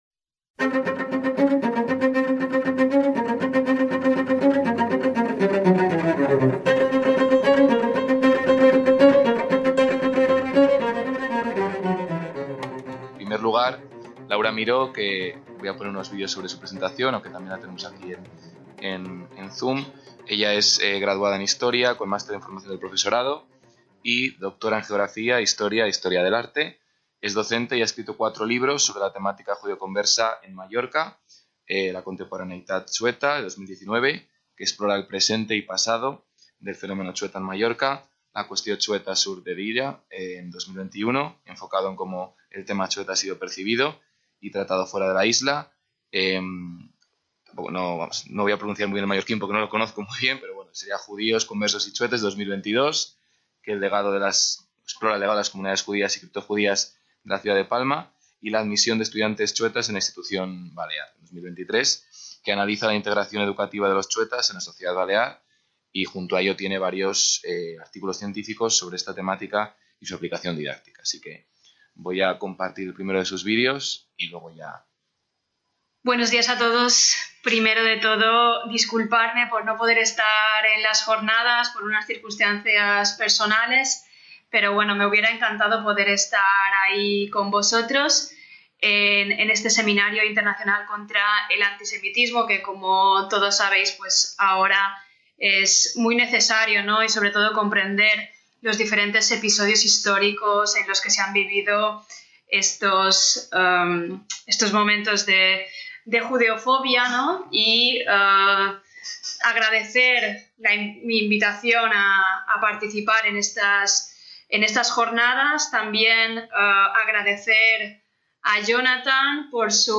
ACTOS "EN DIRECTO" - El origen del antichuetismo se sitúa en los actos de fe de 1691. La publicación de La Fe Triunfante y sus reediciones explican la pervivencia del estigma y la creación de una cuestión de apellidos.